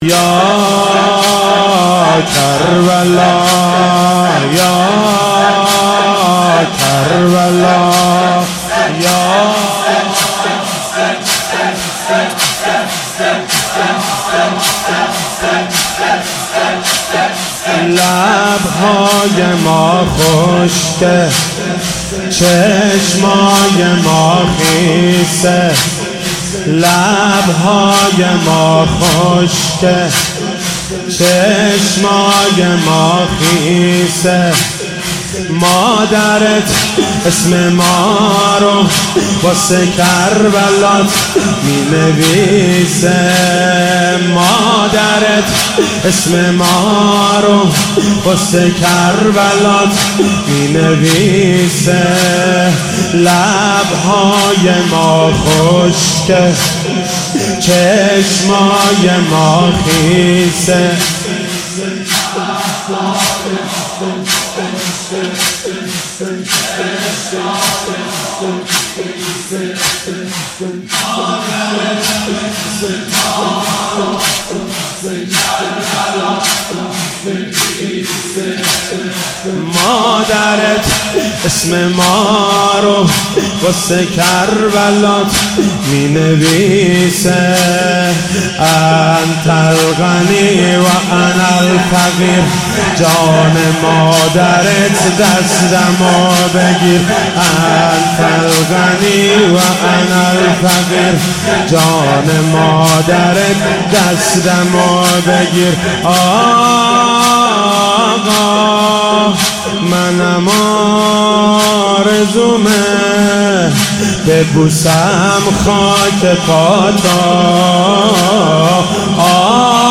محرم 95